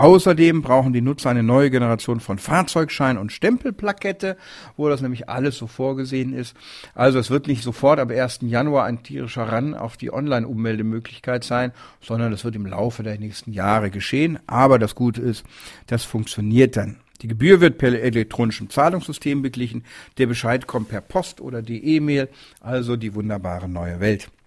O-Töne / Radiobeiträge, Ratgeber, Recht,